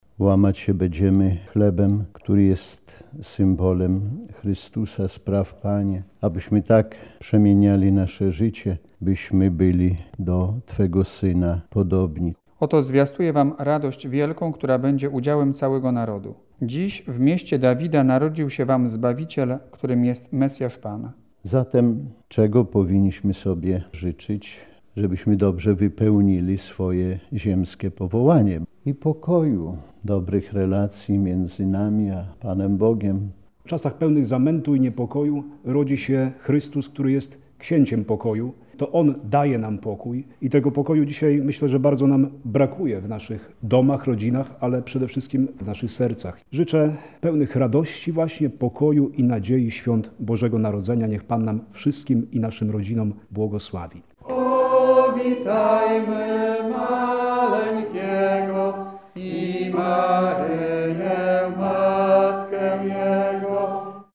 W siedzibie Radia Warszawa przy ul. Floriańskiej 3 odbyło się przedświąteczne spotkanie opłatkowe.
Na zakończenie zebrani odśpiewali kolędę: „Pójdźmy wszyscy do stajenki”.